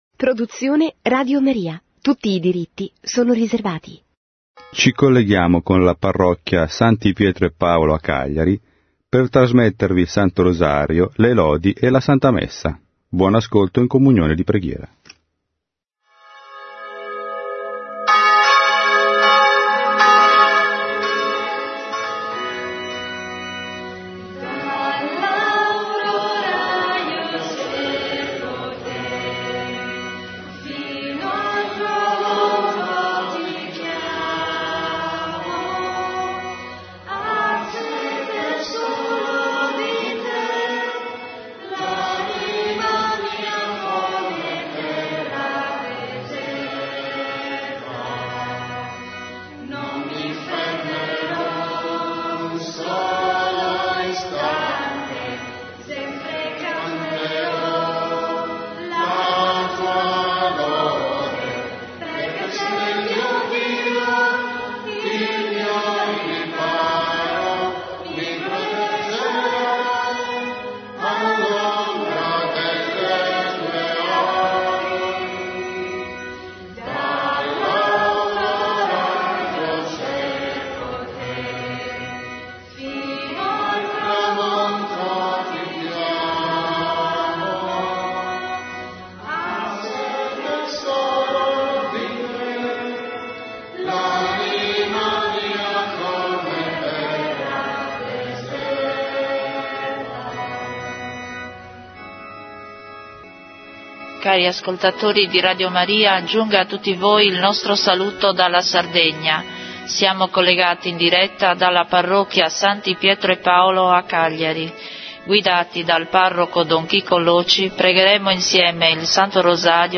Rosario, Lodi Mattutine e S. Messa, in memoria dei santi martiri Paolo Miki e compagni, protomartiri giapponesi, trasmessa su Radio Maria.